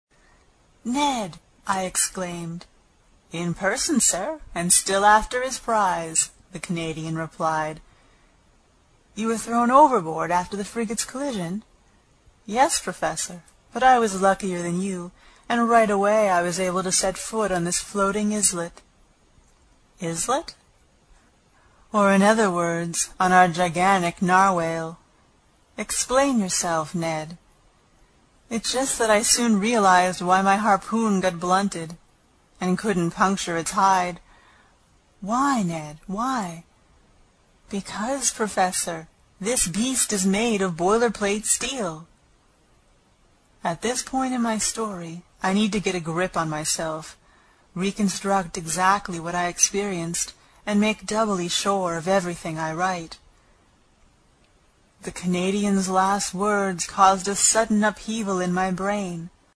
英语听书《海底两万里》第88期 第7章 一种从未见过的鱼(11) 听力文件下载—在线英语听力室
在线英语听力室英语听书《海底两万里》第88期 第7章 一种从未见过的鱼(11)的听力文件下载,《海底两万里》中英双语有声读物附MP3下载